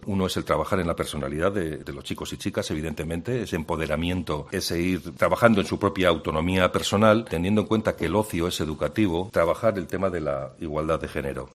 Fernando San Martín, Director de Cooperación de Gipuzkoa
En la rueda de prensa han participado Lorea Bilbao, Diputada de Euskera y Cultura de Bizkaia, Igone Martínez de LunaDiputada de Euskera, Cultura y Deporte de Álava y Fernando San Martín, Director de Cooperación de Gipuzkoa.